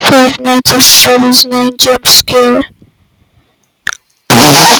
fnaf 9 jump fart
fnaf-9-jump-fart.mp3